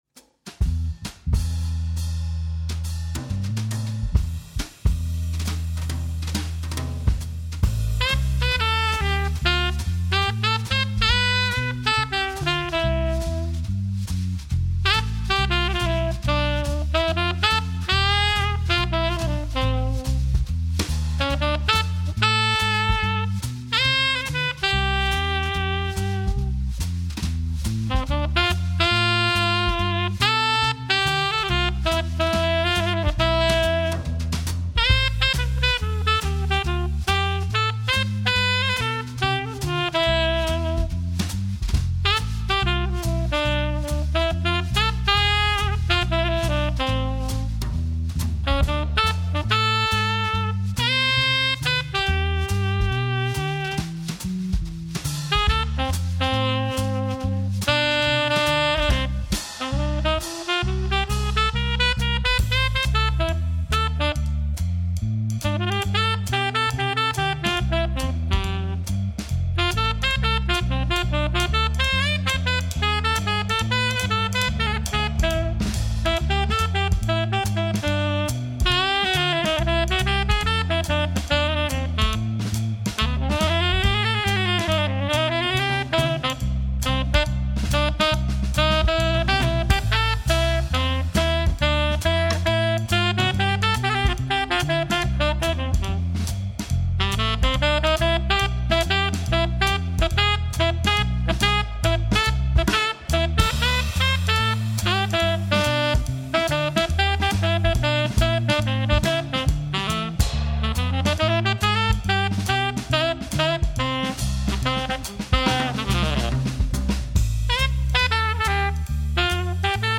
Sax, Bass, Drums